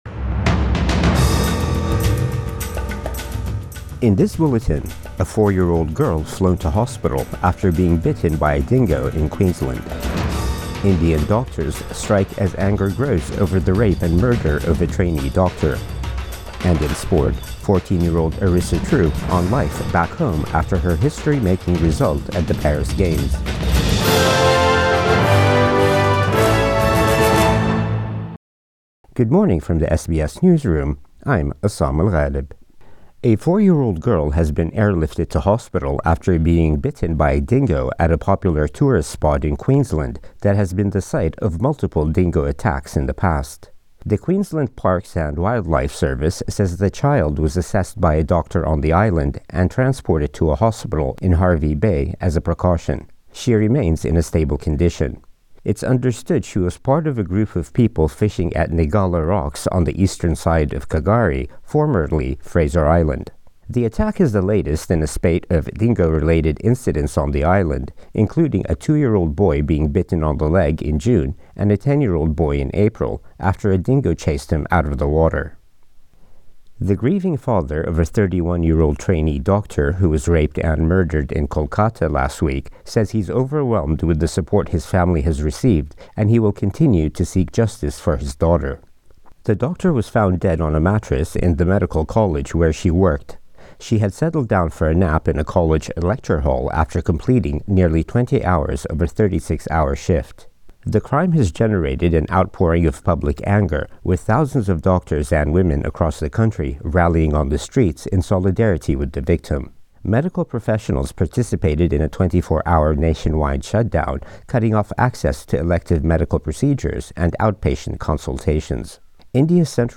Morning News Bulletin 18 August 2024